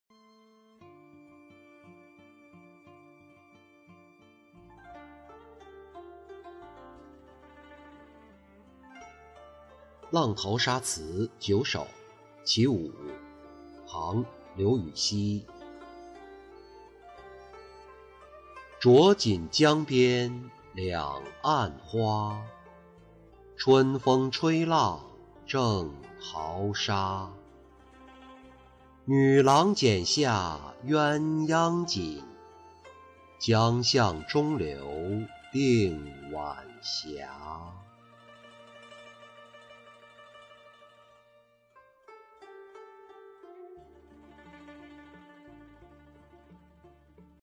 浪淘沙·其五-音频朗读